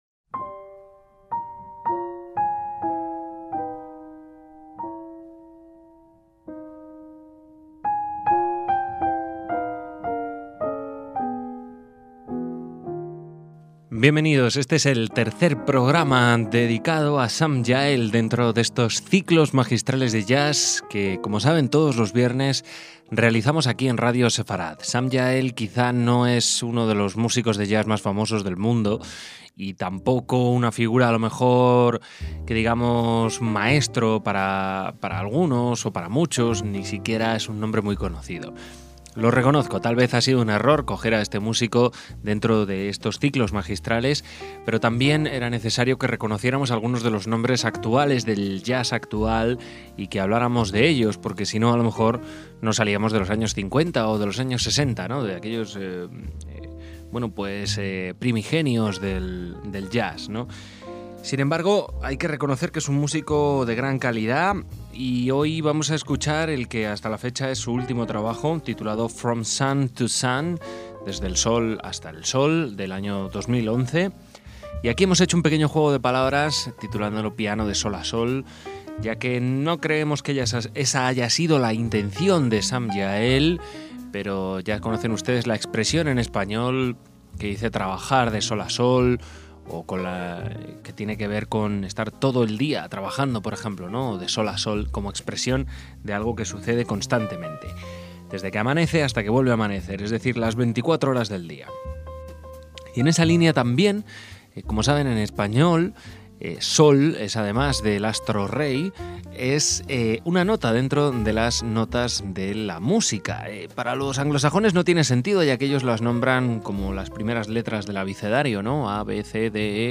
bajo
batería
tecladista